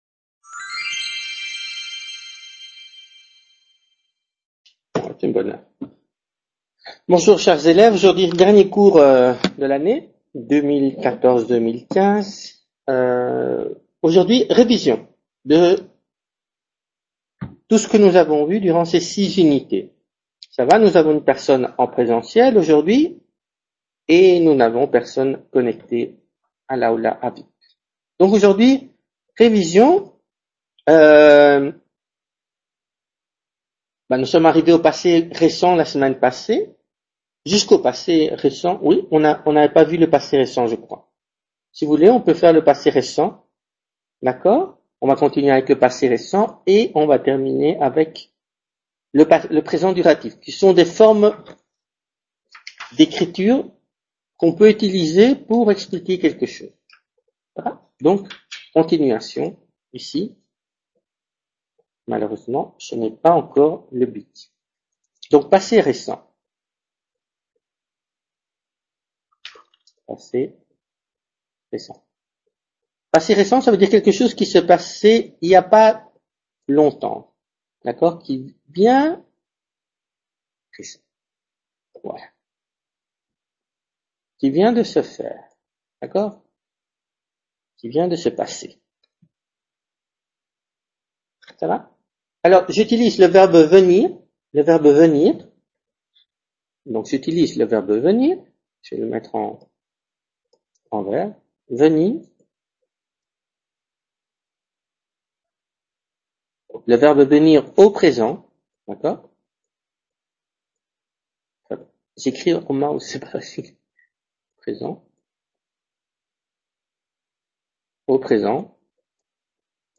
Clase de Francés Niveau Débutant 28/05/2015 | Repositorio Digital